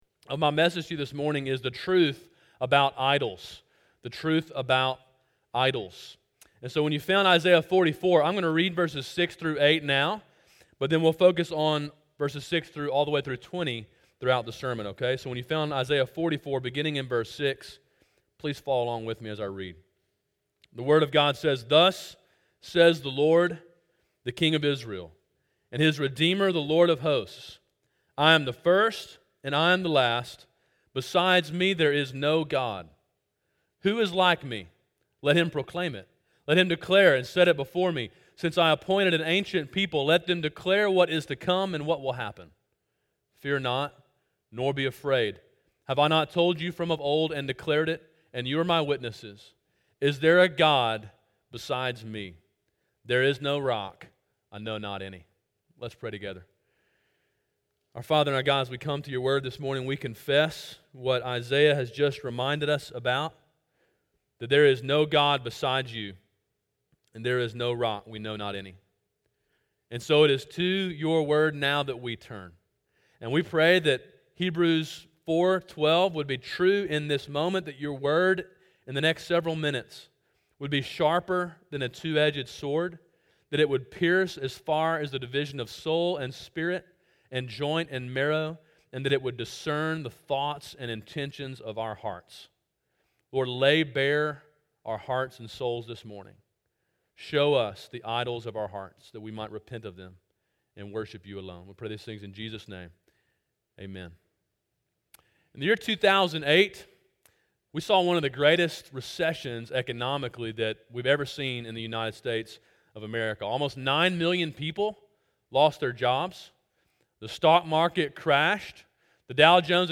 Sermon: “The Truth about Idols” (Isaiah 44:6-20)